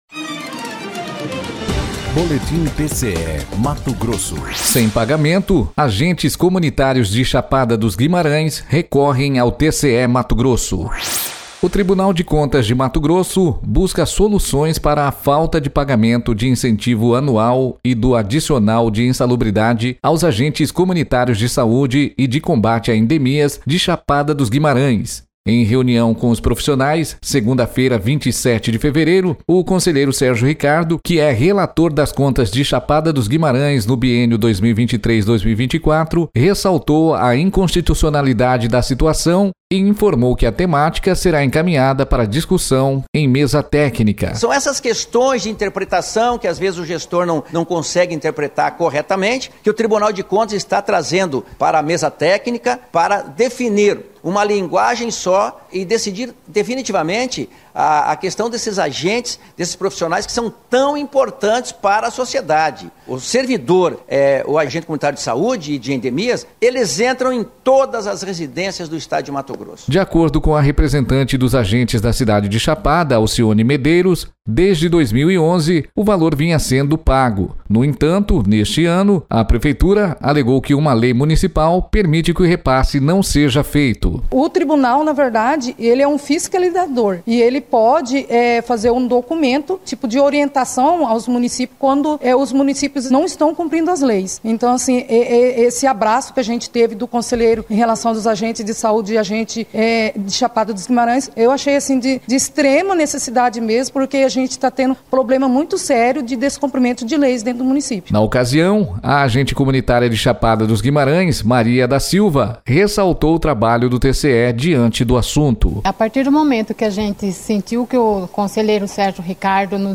Sonora: Sérgio Ricardo – conselheiro do TCE-MT